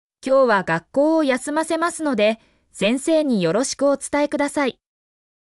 mp3-output-ttsfreedotcom-8.mp3